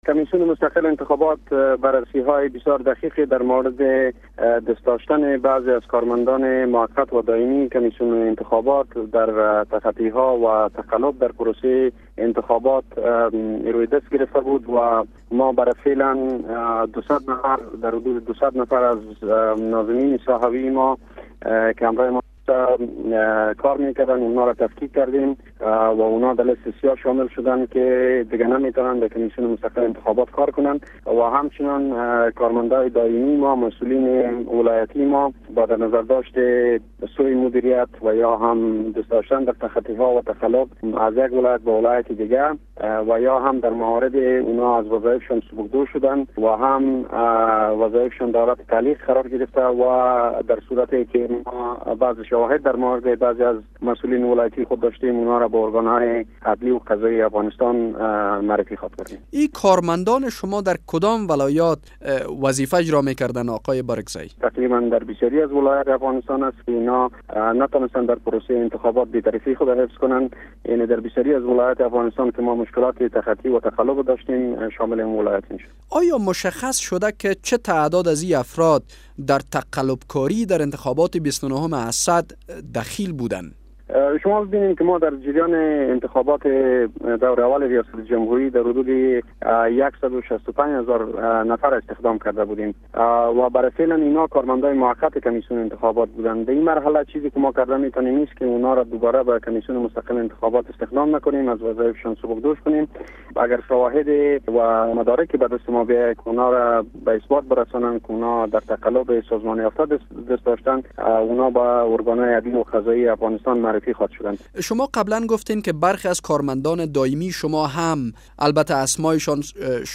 مصلحبه